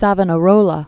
(săvə-nə-rōlə, sävō-nä-), Girolamo 1452-1498.